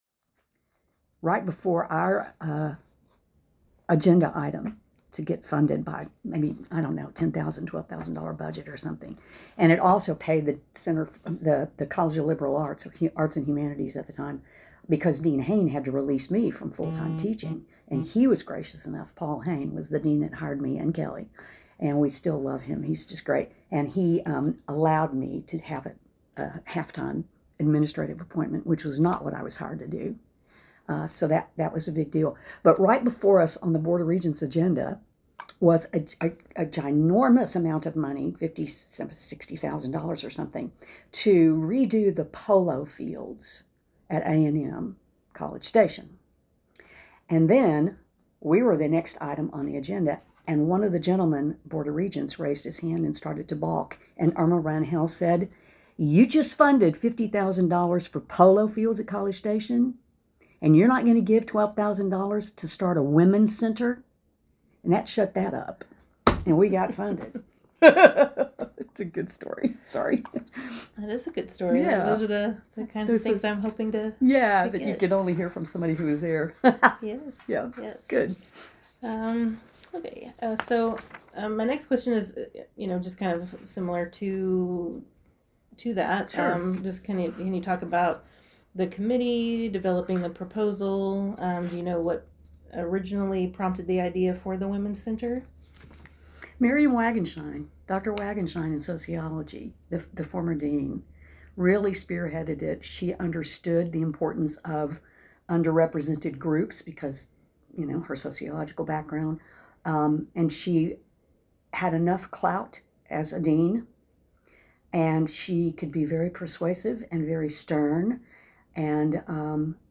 Transcript Excerpt of Interview